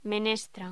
Locución: Menestra
voz